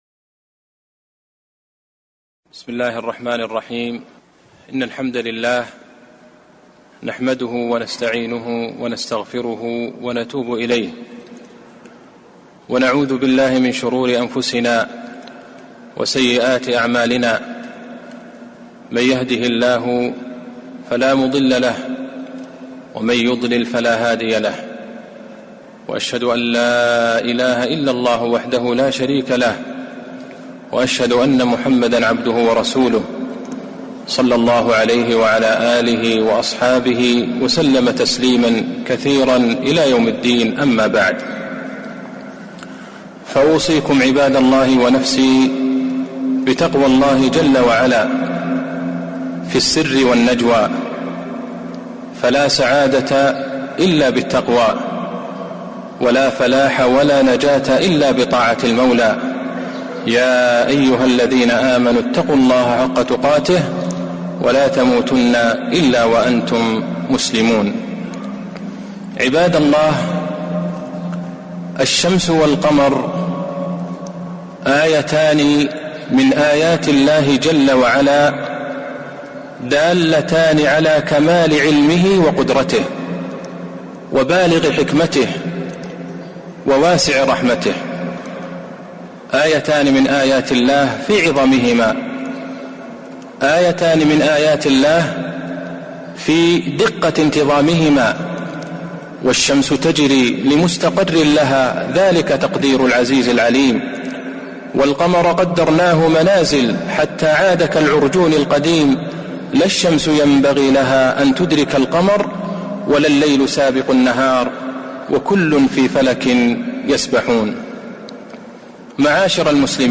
خطبة الكسوف 29 شوال 1441هـ.